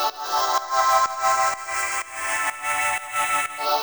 GnS_Pad-alesis1:4_125-E.wav